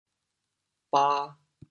“把”字用潮州话怎么说？
ba2.mp3